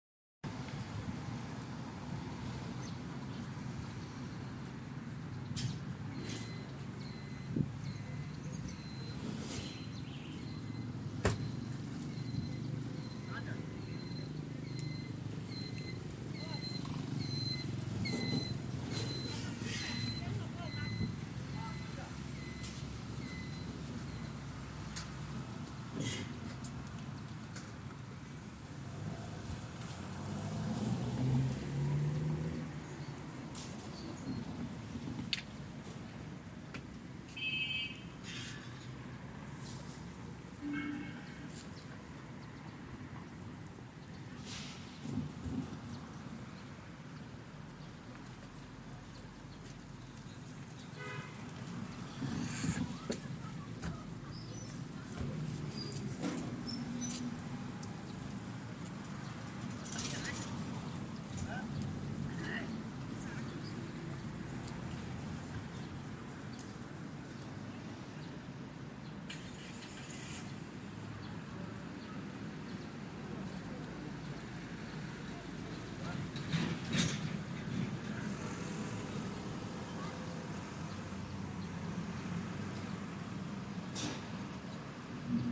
路口的汽车声
描述：记录一个路口的汽车声
标签： 汽车 路口
声道立体声